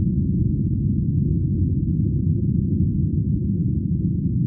Звуки ветра. Sounds of wind.
Звук порывистый ветер в каньоне.